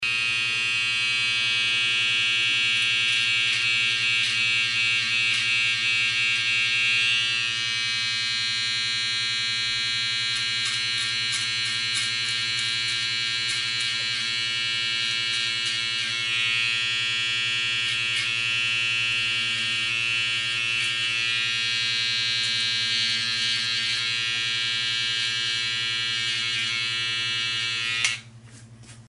Звуки триммера выравнивают кант